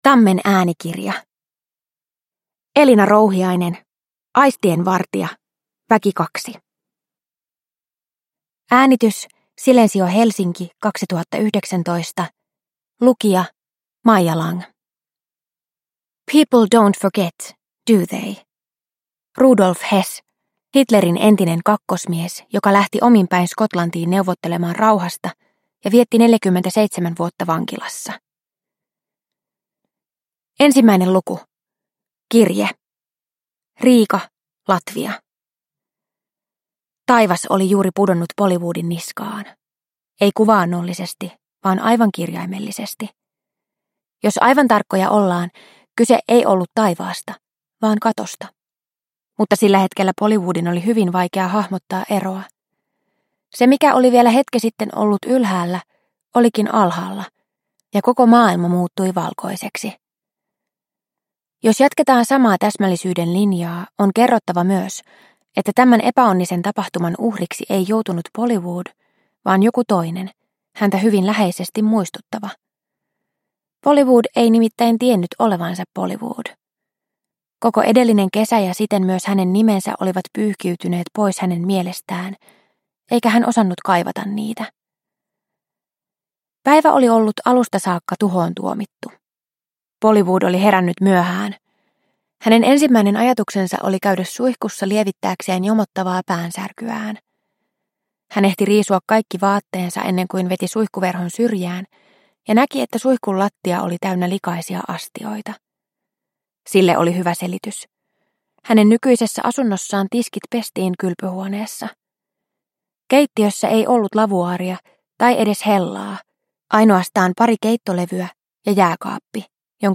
Aistienvartija – Ljudbok – Laddas ner